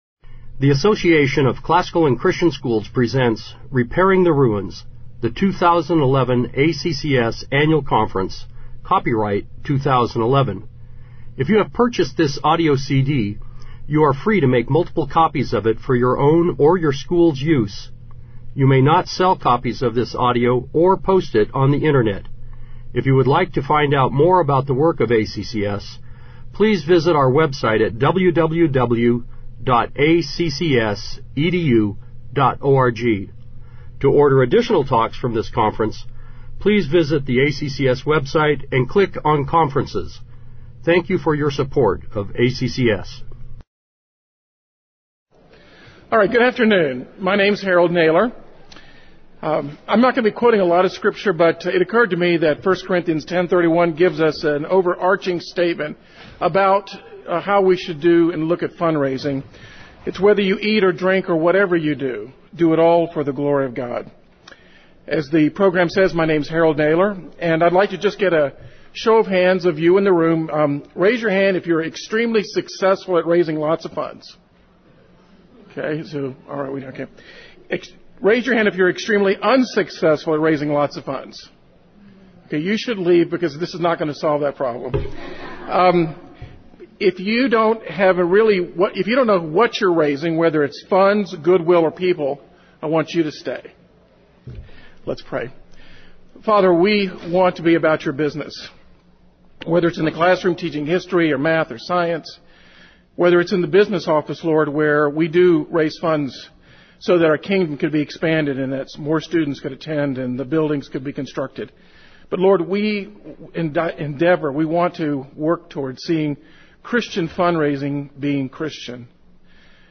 2011 Workshop Talk | 0:53:50 | All Grade Levels, Theology & Bible